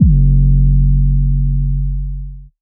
808 (EDM-Trap).wav